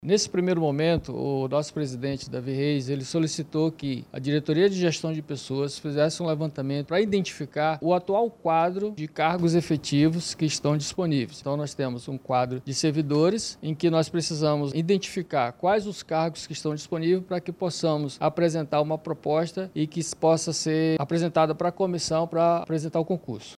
SONORA-1-LEVANTAMENTO-CARGOS-CMM-.mp3